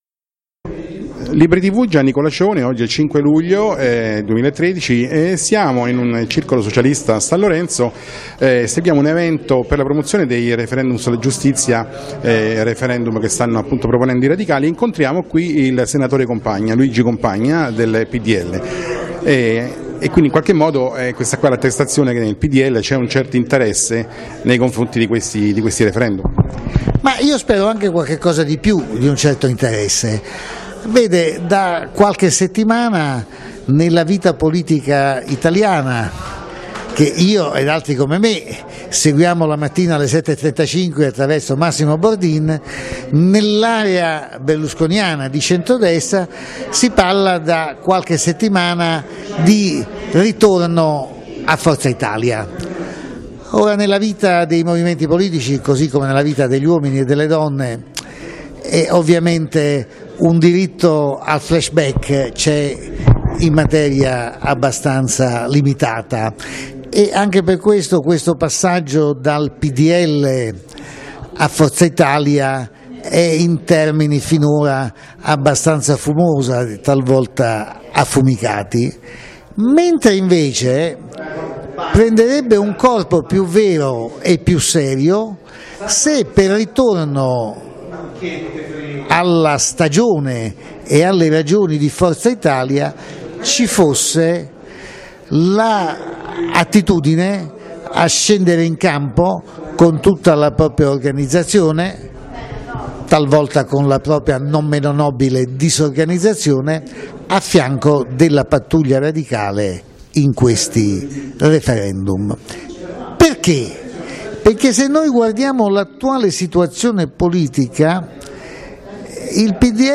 Intervista al al Senatore Luigi Compagna (PdL) Professore ordinario di Storia delle dottrine politiche alla Facoltà di Scienze Politiche della LUISS Guido Carli di Roma.